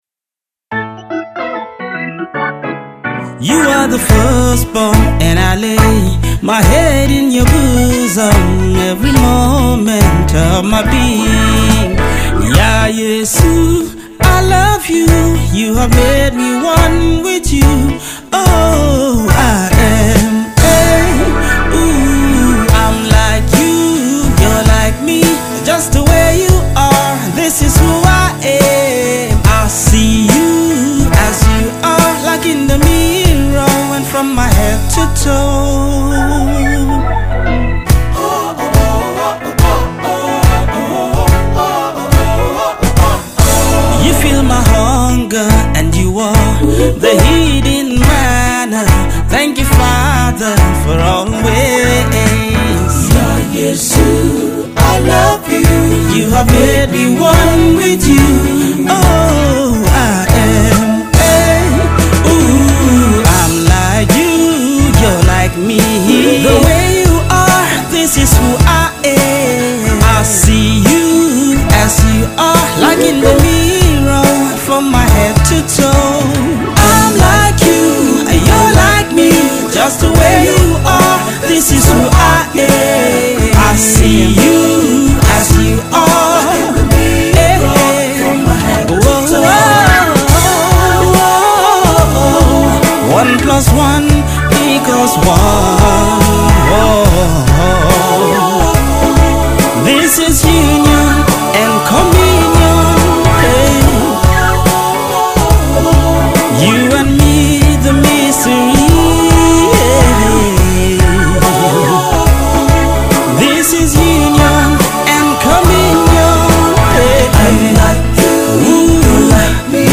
Music intro: "Image of the Firstborn" by Bayila Dalaky